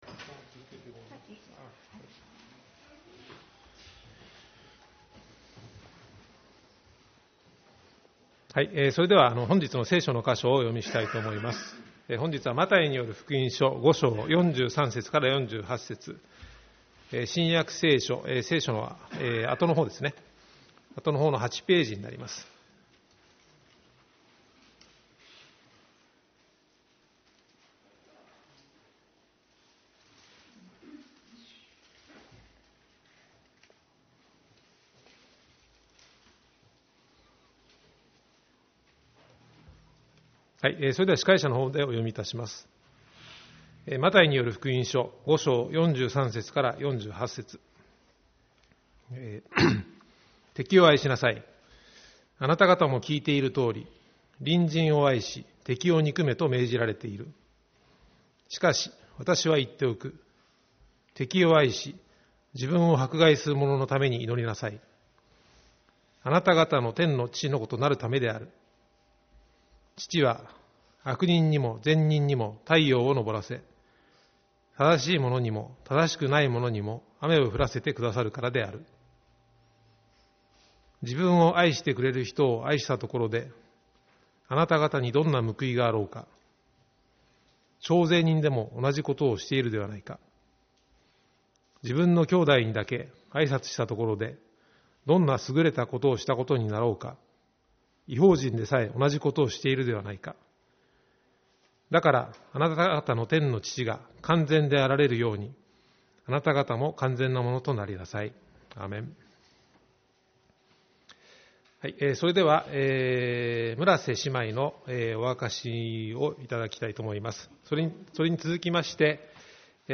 主日礼拝 「愛を追い求めよ」